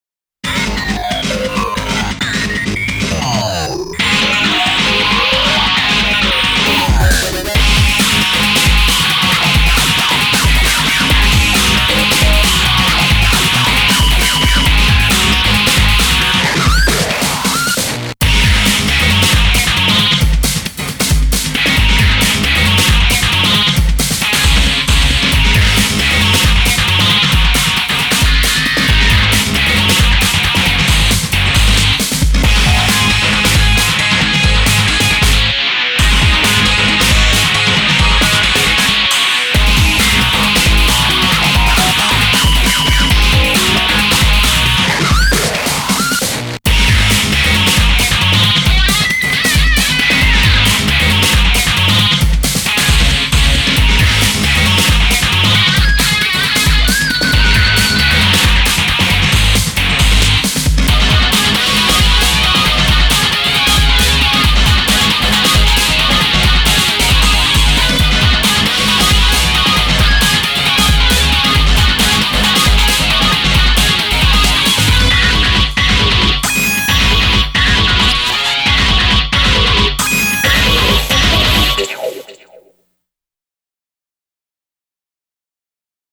BPM135
GENRE: DIGI-ROCK